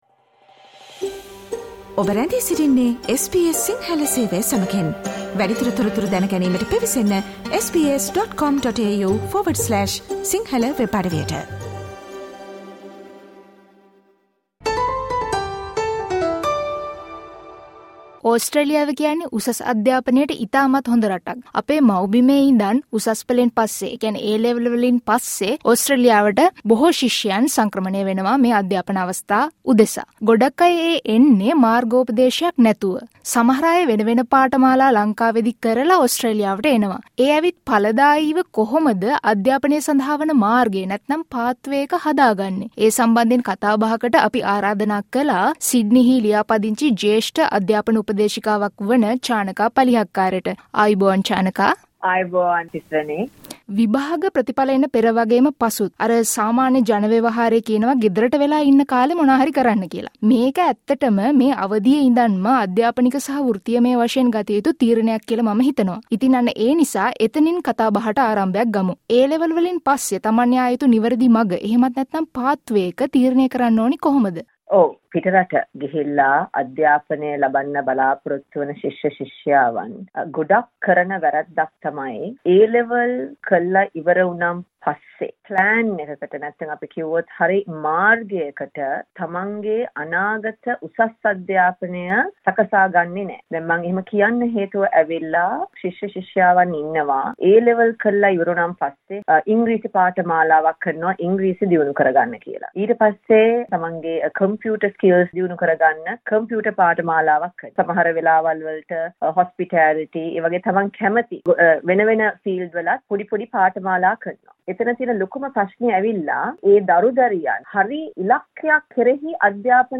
SBS සිංහල සේවාව කළ සාකච්ඡාවට සවන් දෙන්න.